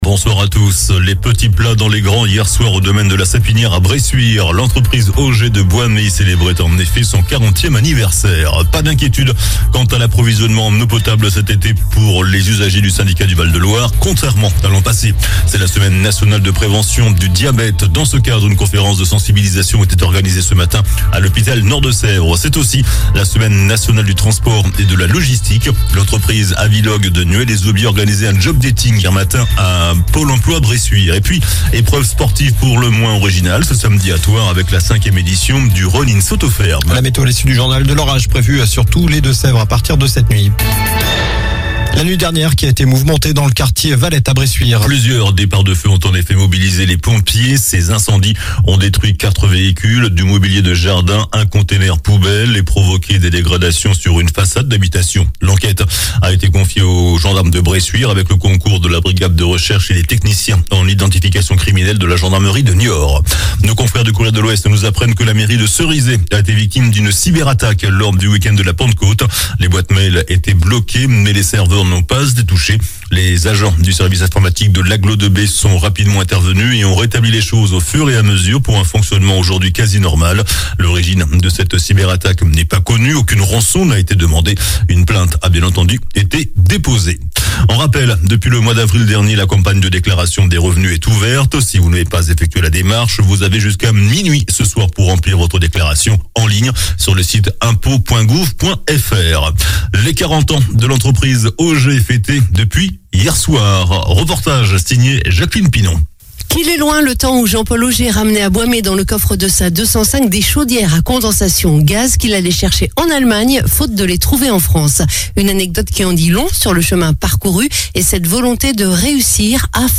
JOURNAL DU JEUDI 08 JUIN ( SOIR )